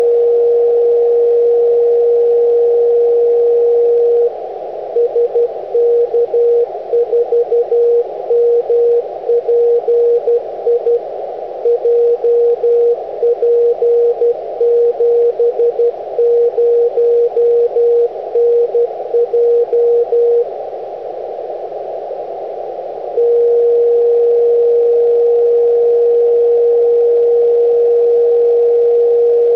SK4MPI/B, the Aurora warning beacon, this time on tropo for a change: 😉